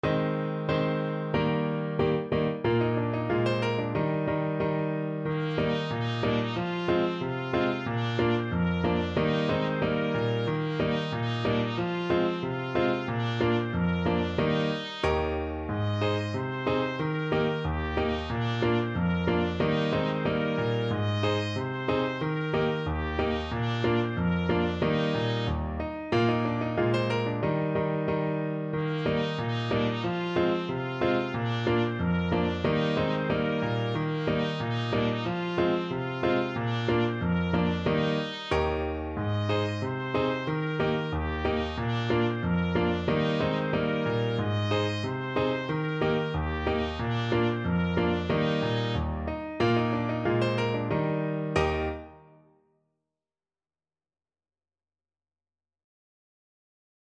Trumpet
Eb major (Sounding Pitch) F major (Trumpet in Bb) (View more Eb major Music for Trumpet )
2/2 (View more 2/2 Music)
Jolly =c.92
Classical (View more Classical Trumpet Music)
Swiss
vo_luzern_uf_waggis_zue_TPT.mp3